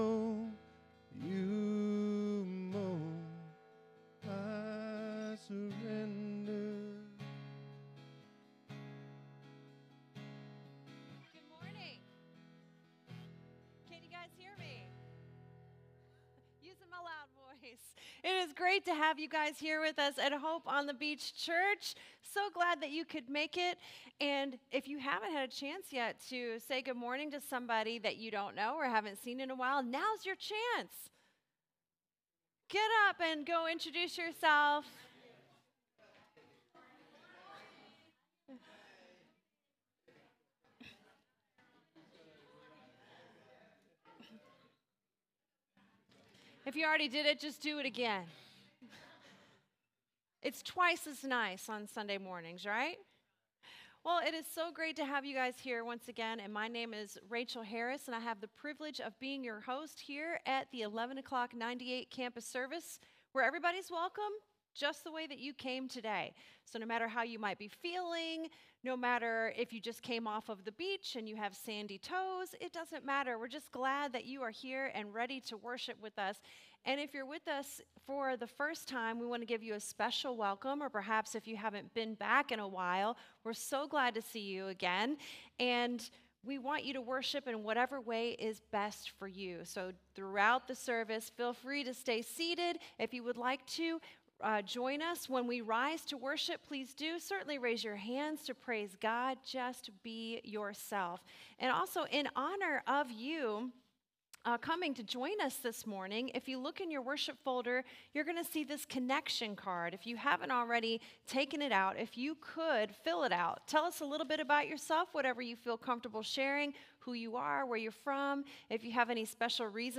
SERMON DESCRIPTION God is a sovereign God who positions His people to be in the right places at the right times to accomplish His purposes!